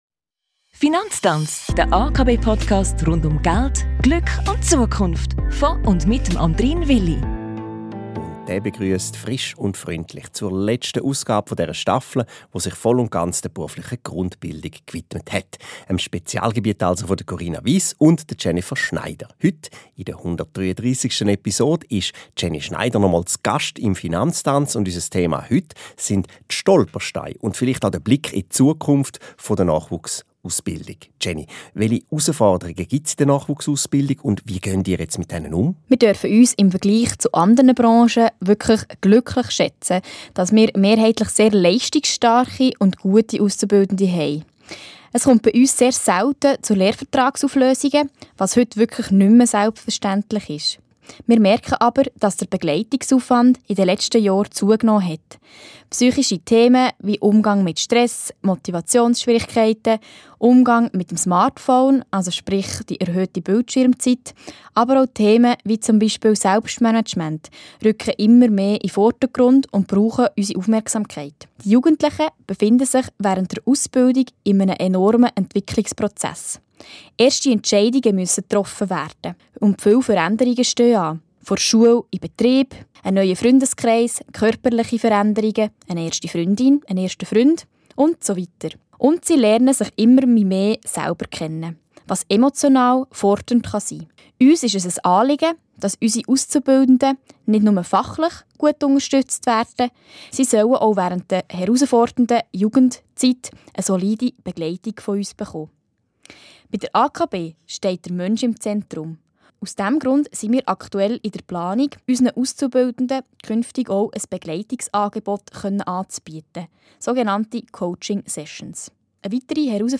Was das heisst, verraten die beiden Expertinnen im